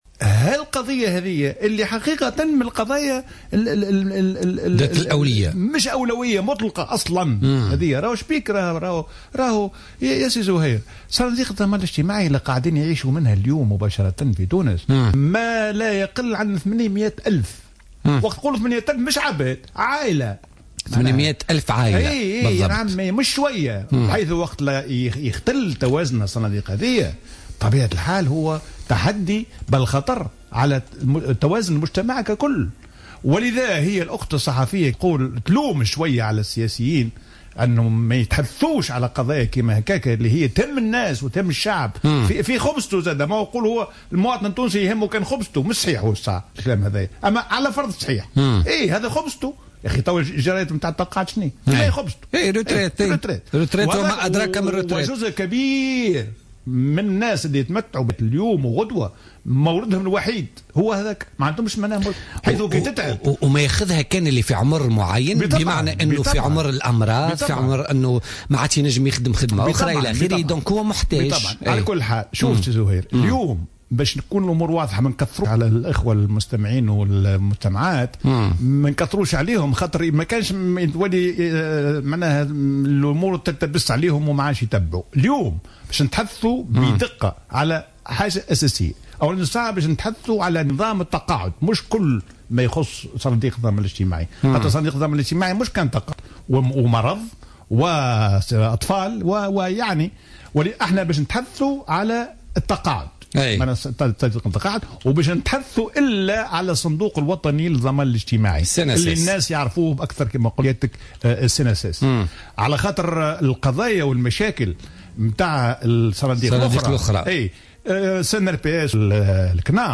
قال حسين الديماسي الخبير الاقتصادي وضيف برنامج بوليتكا لليوم الجمعة 15 جانفي 2016 إن الصناديق الاجتماعية تعرف عجزا كبيرا قد يؤدي إذا تراكم إلى عدم قدرتها على دفع جرايات التقاعد للمواطنين وخاصة الصندوق الوطني للضمان الاجتماعي "cnss ".